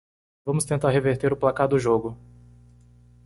Pronounced as (IPA)
/plaˈka(ʁ)/